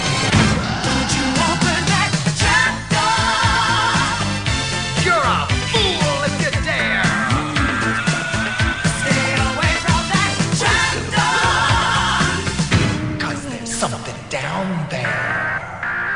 trap-door_20944.mp3